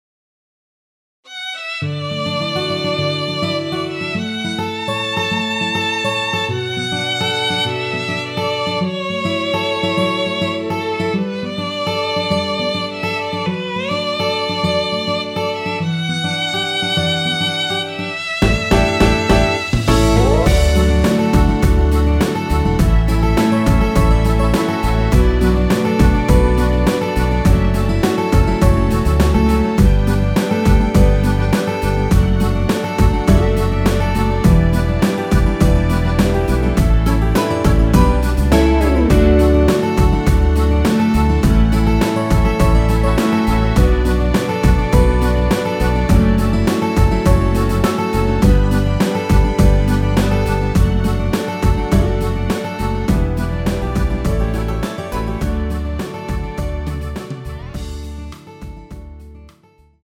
원키에서(-4)내린 MR입니다.
D
앞부분30초, 뒷부분30초씩 편집해서 올려 드리고 있습니다.
중간에 음이 끈어지고 다시 나오는 이유는